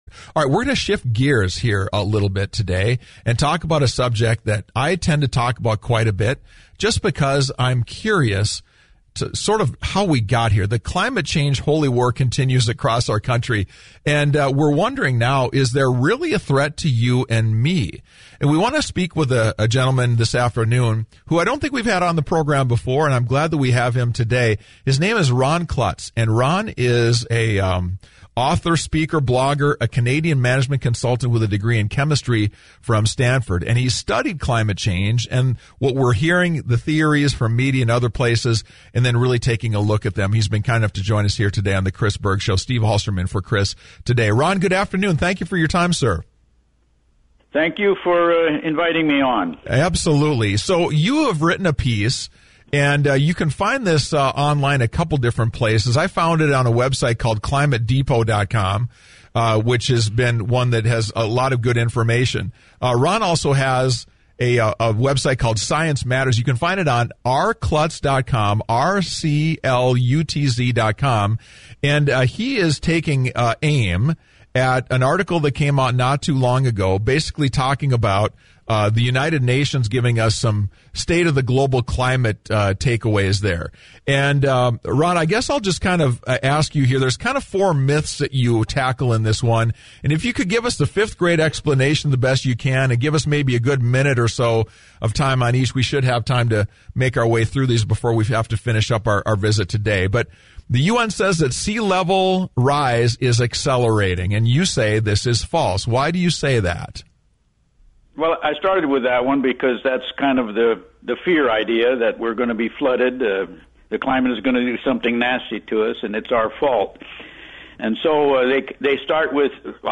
Tuesday I was interviewed on the radio regarding my rebutting UN False Alarms.
With some help from my grandson, you can hear my segment below (a few commercials included).